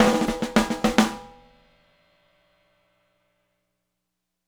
Retro Funkish Beat Ending.wav